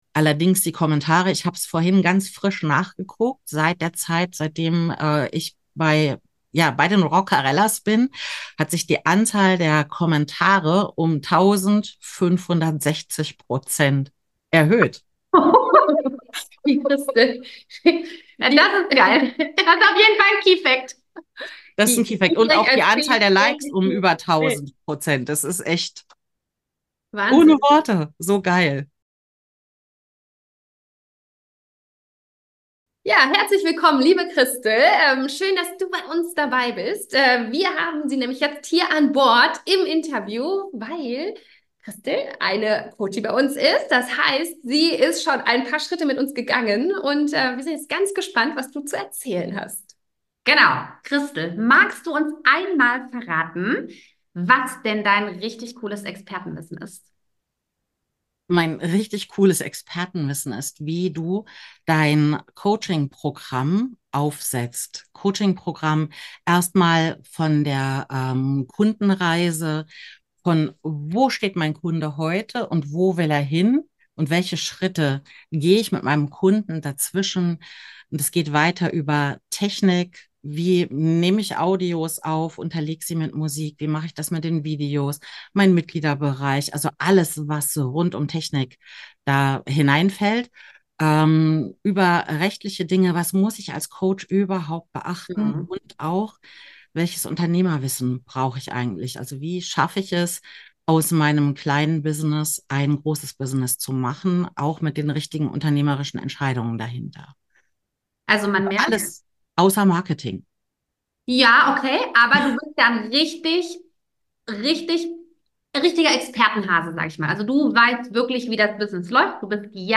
Über 500 aktive Mitglieder pro Tag in der Facebook-Gruppe - Erfolgsinterview